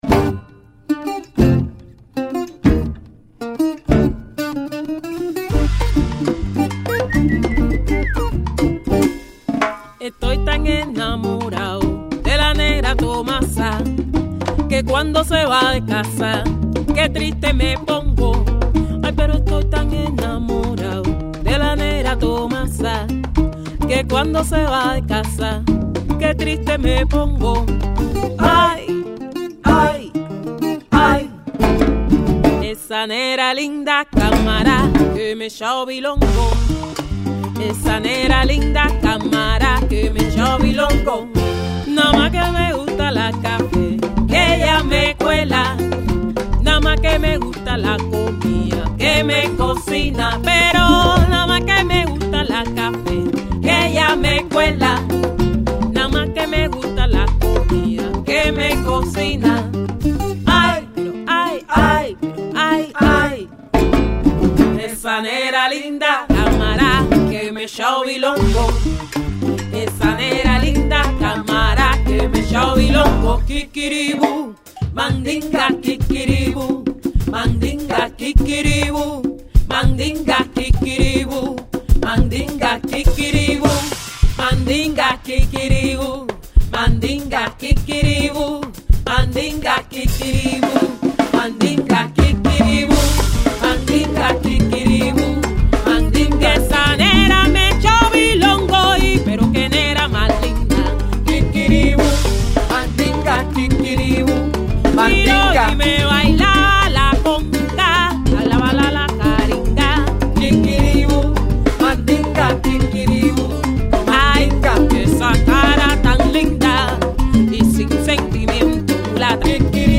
• 4 and 5-piece band options, including percussion & trumpet